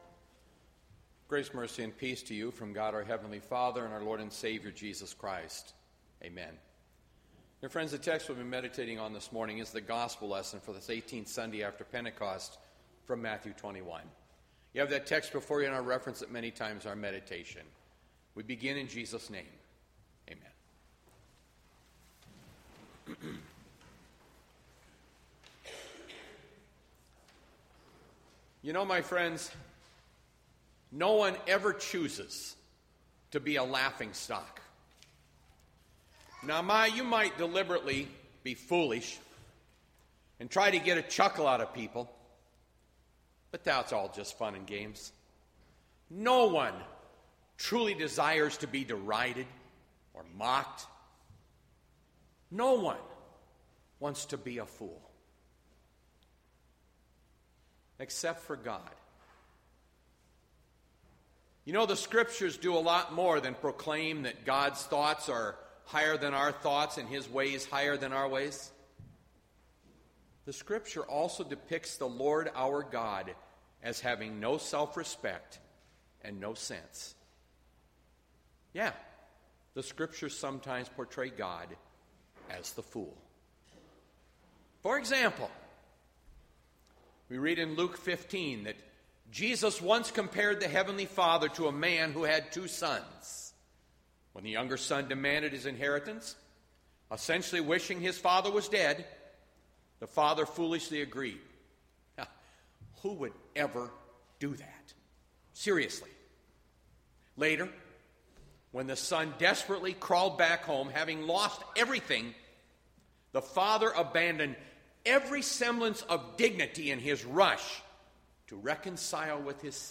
Bethlehem Lutheran Church, Mason City, Iowa - Sermon Archive Oct 4, 2020